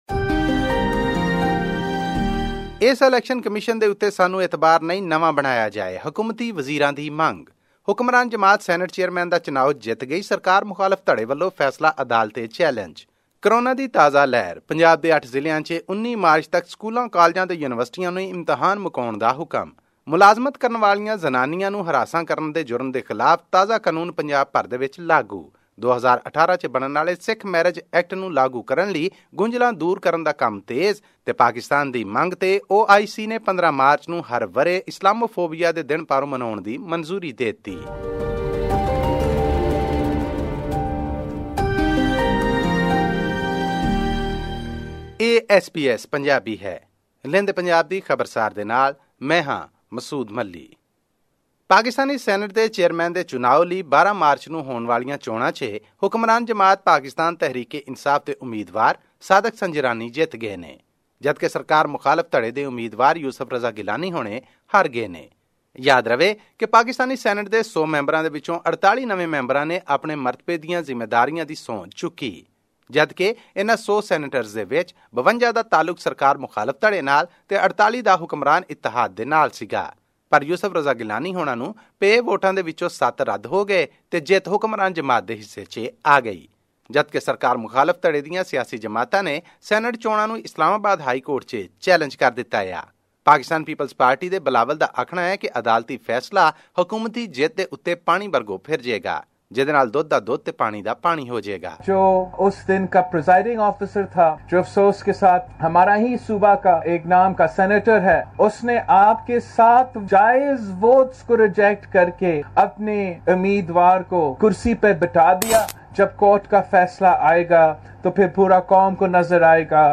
The Imran Khan-led government on Monday called for the resignation of the chief election commissioner and the reconstitution of the Election Commission of Pakistan (ECP), which it said failed to hold the recently-held elections in a transparent manner. This and more in our weekly news segment from Pakistan’s Punjab province.